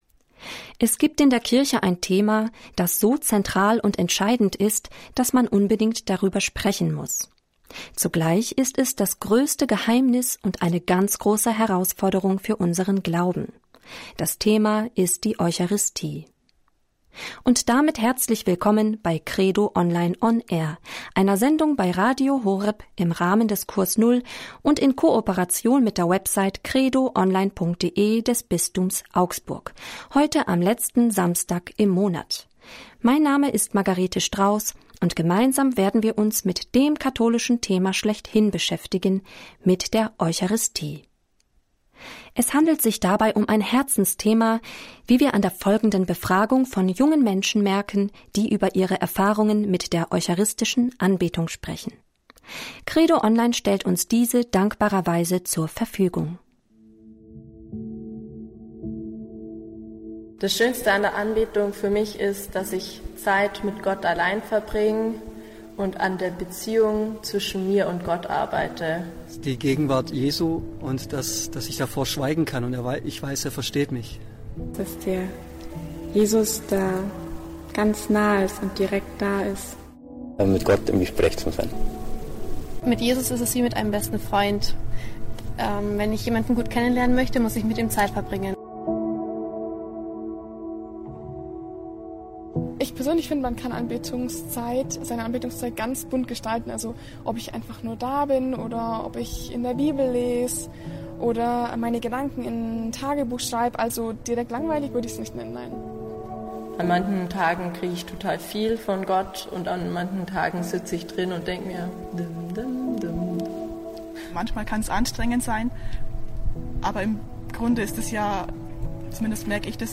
Sendung vom 30. Oktober 2021 bei Radio Horeb